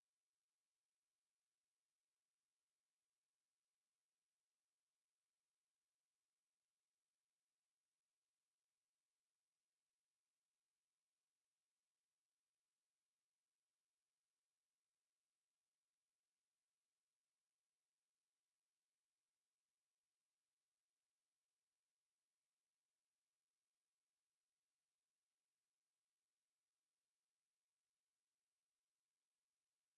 Звуки ультразвука
Звук, привлекающий летучих мышей ультразвуком